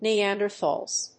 /niˈændɝˌθɔlz(米国英語), ni:ˈændɜ:ˌθɔ:lz(英国英語)/